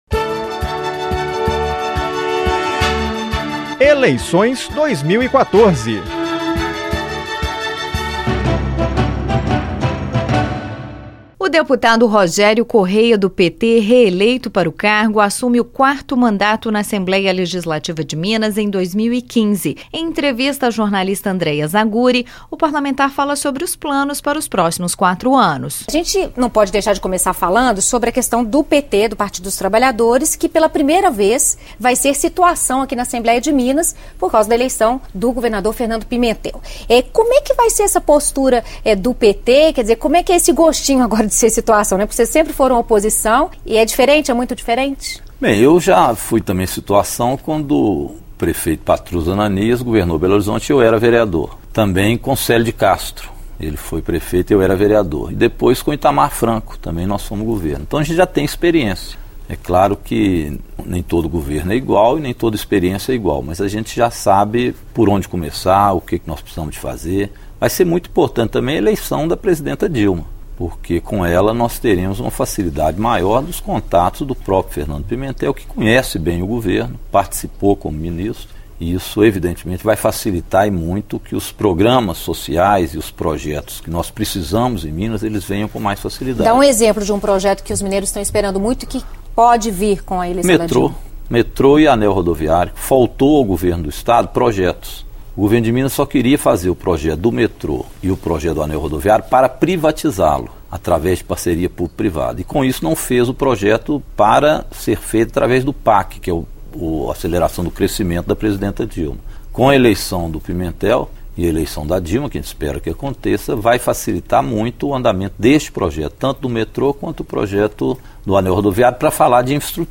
Entrevistas